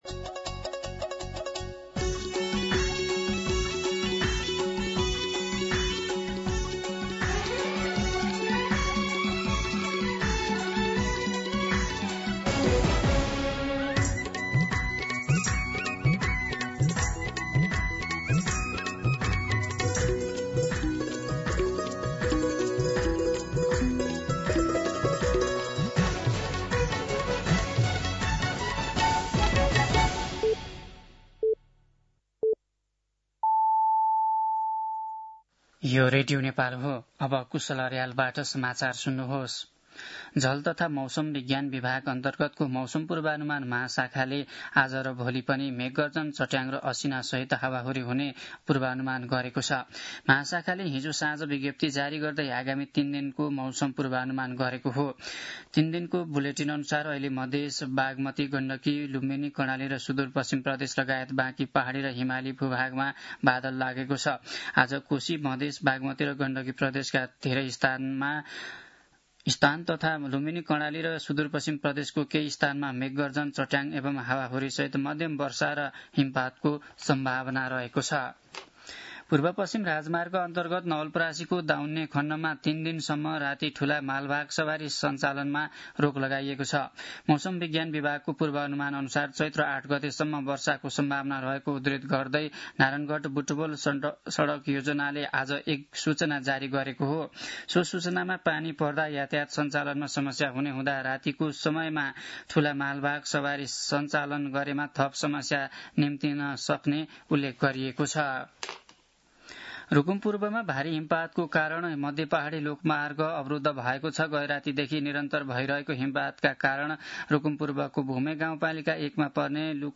मध्यान्ह १२ बजेको नेपाली समाचार : ७ चैत , २०८२
12-pm-Nepali-News-4.mp3